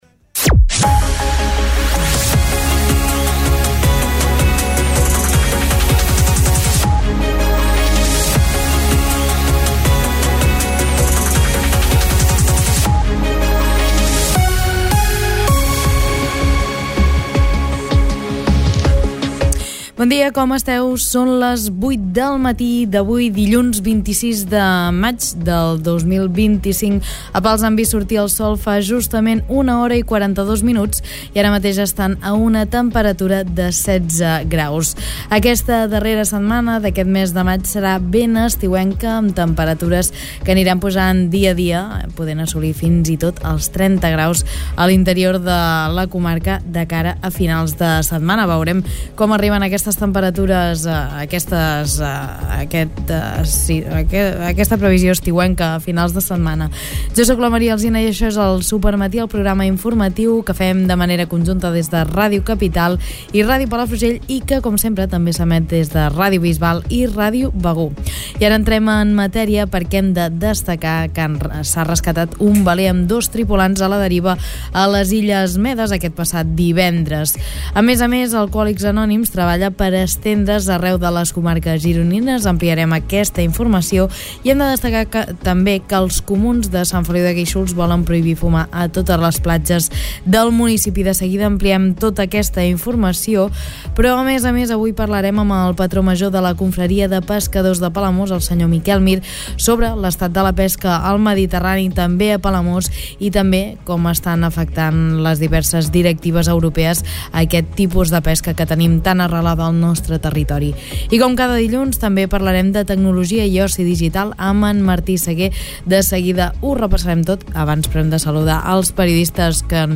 Escolta aquí l'informatiu d'aquest dimarts